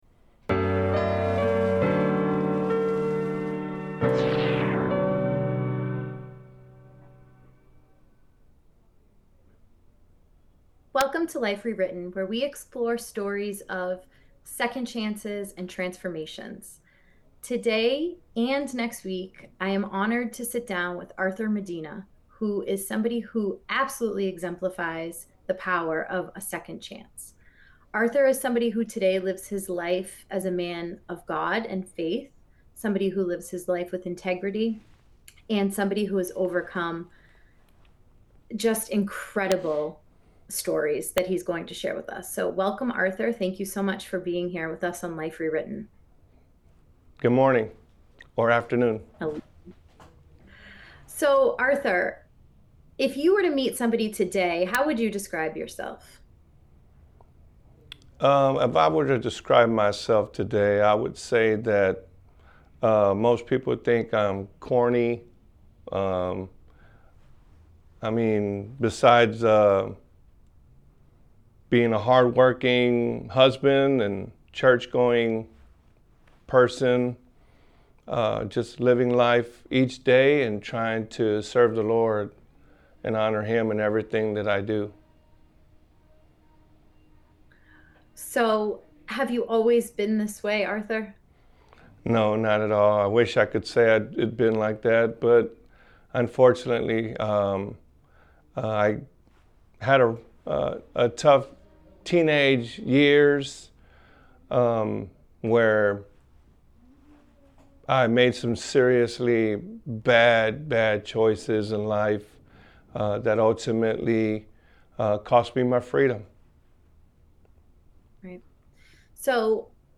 This emotional conversation dives into the struggles that shape us, the pivotal moments that wake us up, and the courage it takes to rewrite our lives one decision at a time.